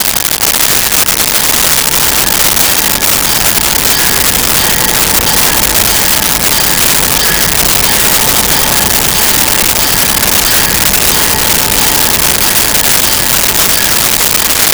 Train Crossing Bell
Train Crossing Bell.wav